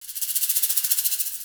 Perc (16).wav